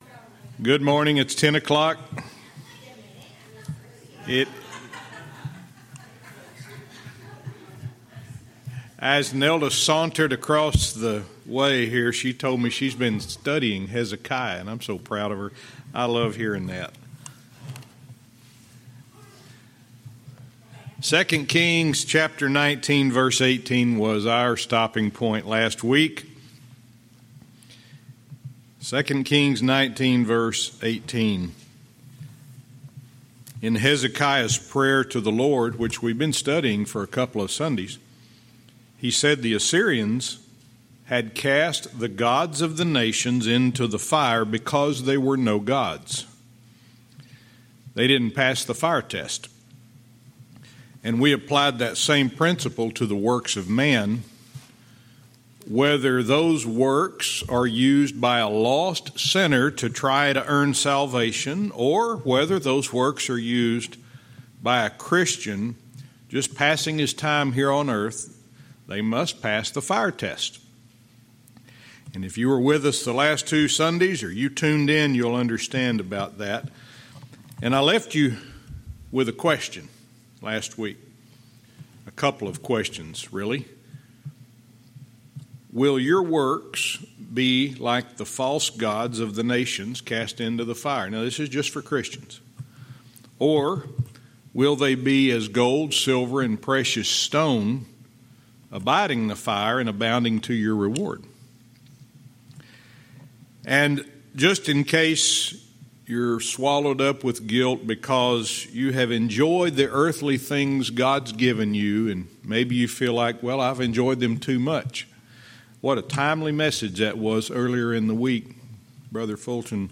Verse by verse teaching - 2 Kings 19:18-19